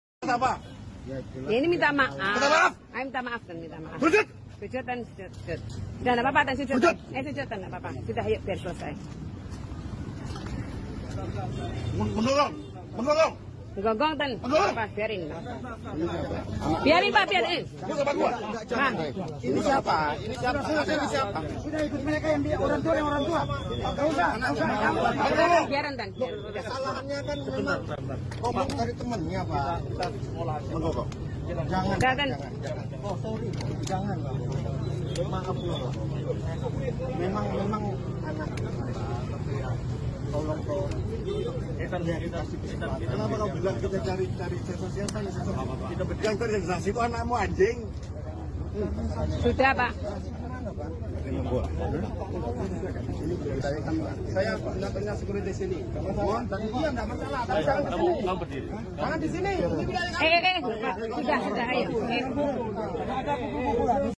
Kepala Bidang Humas Polda Jatim sound effects free download By inilahcom 14953 Downloads 10 months ago 64 seconds inilahcom Sound Effects About Kepala Bidang Humas Polda Jatim Mp3 Sound Effect Kepala Bidang Humas Polda Jatim Kombes Pol Dirmanto menggelar konferensi pers di Polrestabes Surabaya, Rabu (13/11/2024).